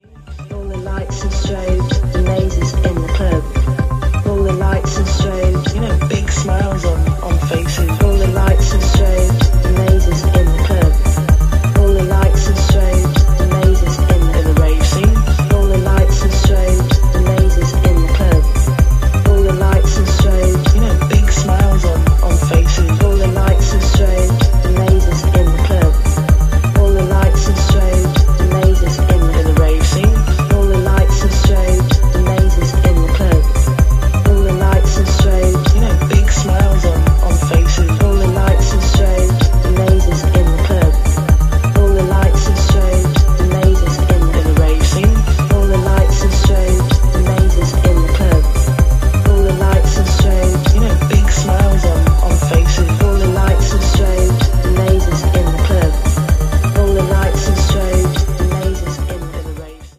supplier of essential dance music
Drum and Bass Breaks